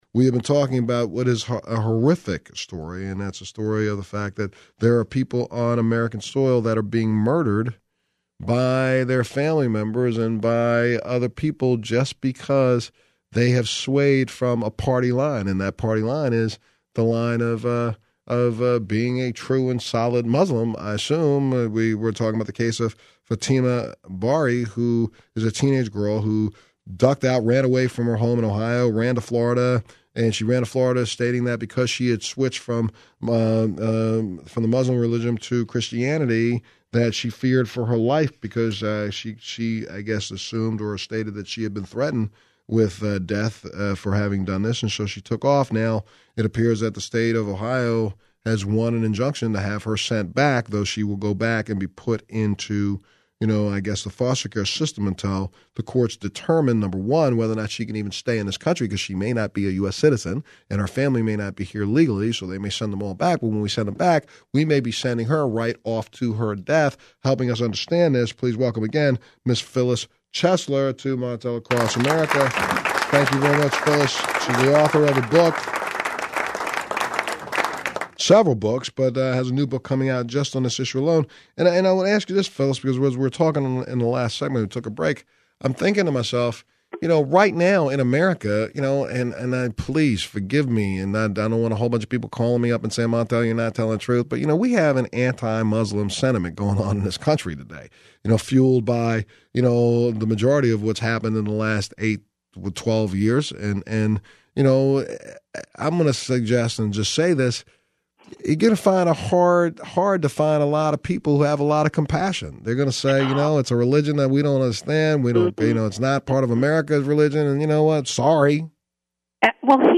Phyllis Chesler interviewed by Montel Williams about honor killings | Phyllis Chesler Organization